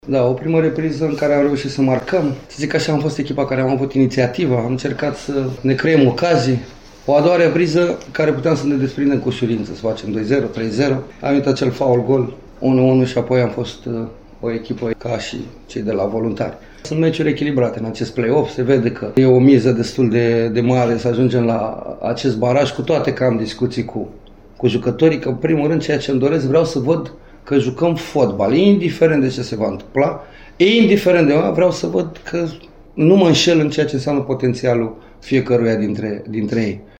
Antrenorul rosso-nerrilor, Flavius Stoican, regretă că echipa lui nu a putut marca mai mult, atunci când a avut ocazia: